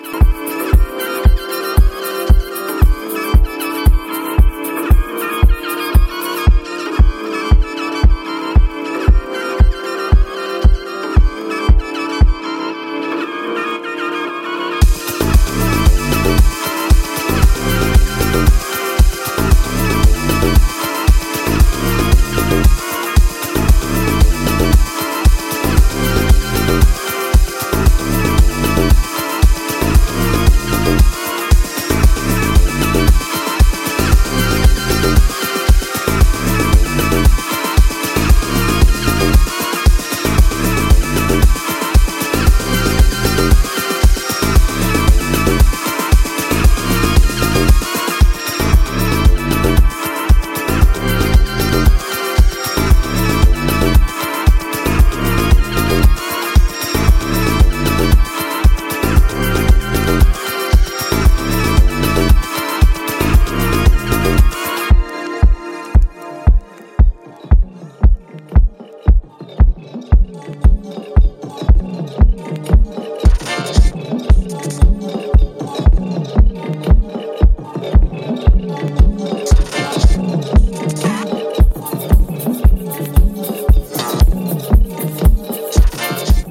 House Ambient